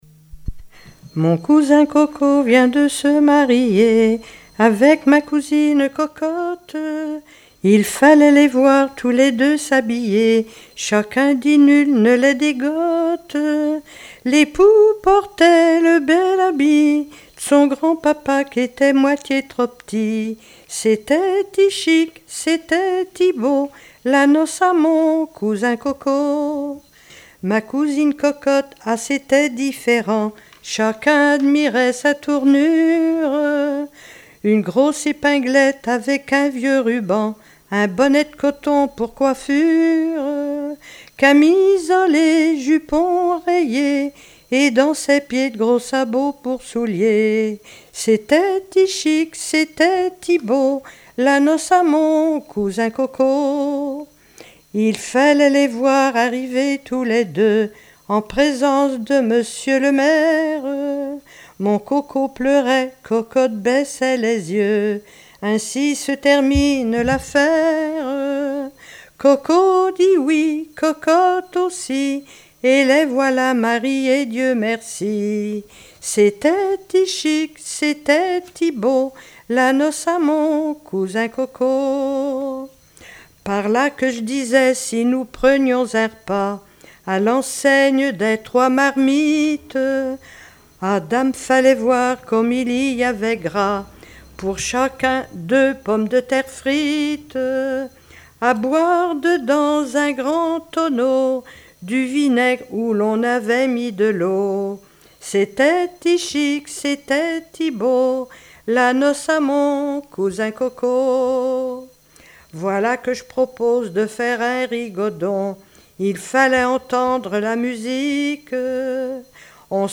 Moutiers-sur-le-Lay
Pièce musicale inédite